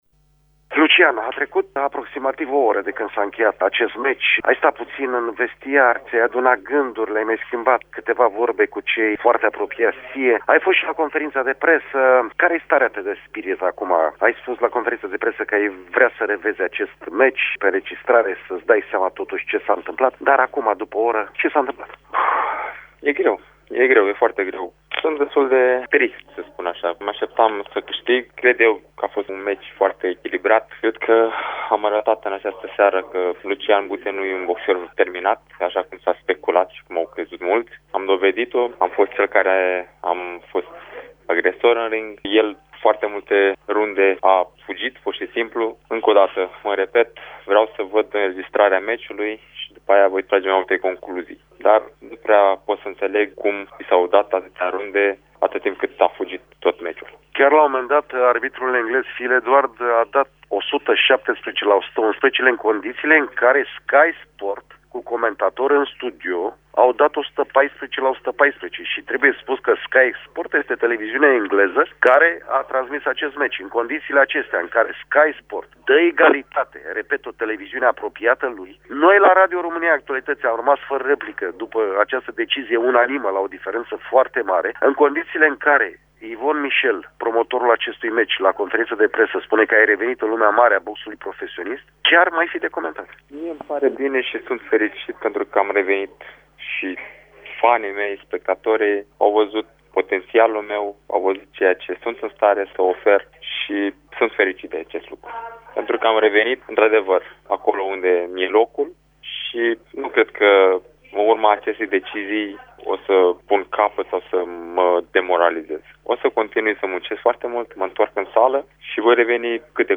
Interviu în exclusivitate cu Lucian Bute